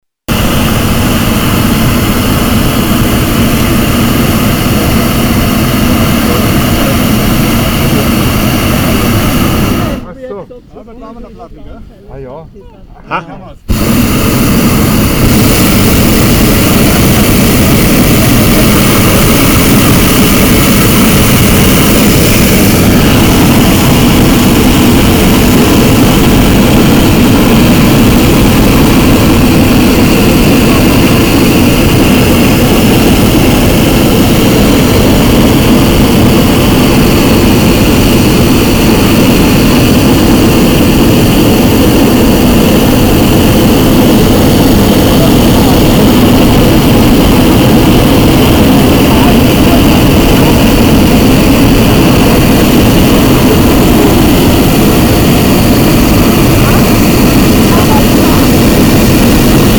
Audioaufnahme des Brenners: